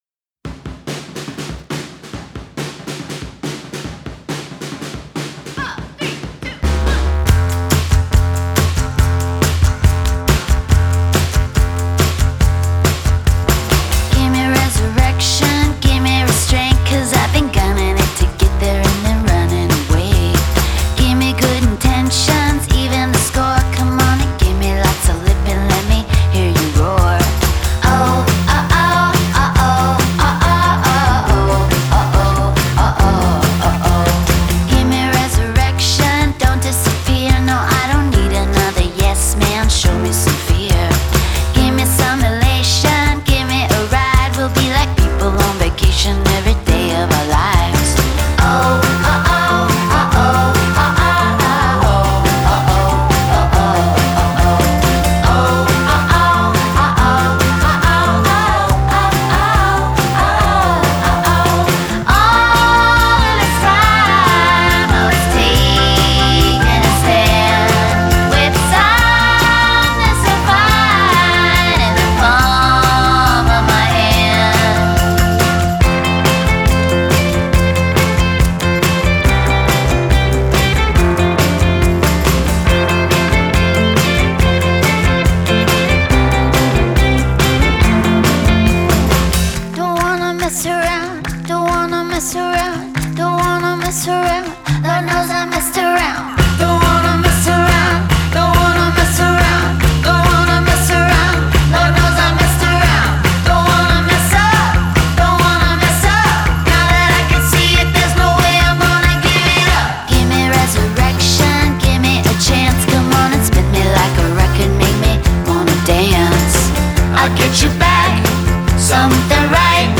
Genre: Singer/Songwriter, Indie Pop, Rock, Folk